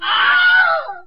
Wilhelm Scream Sound Effect Free Download
Wilhelm Scream